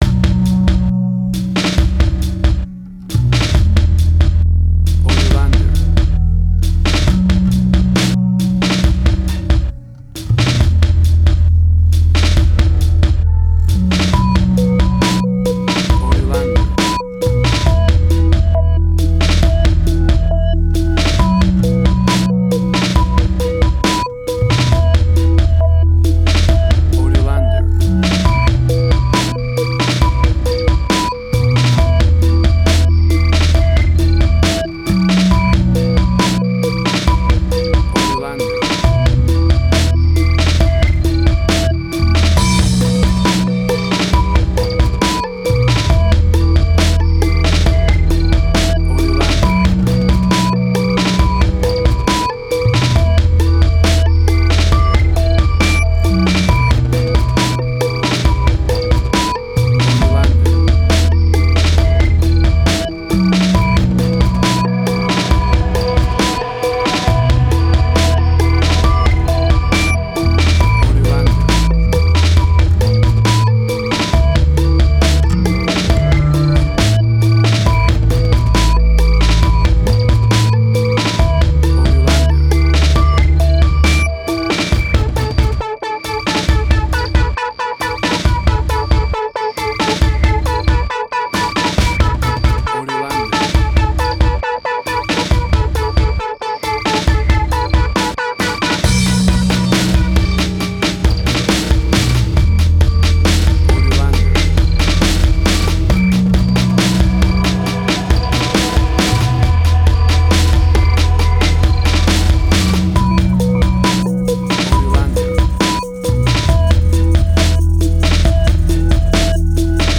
Trip Hop
emotional music
Tempo (BPM): 69